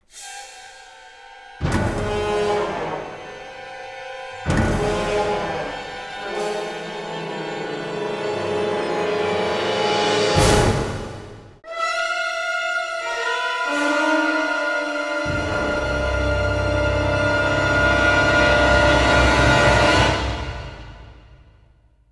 Slightly shorter, also the samples have gaps between them
This file is an audio rip from a(n) PlayStation game.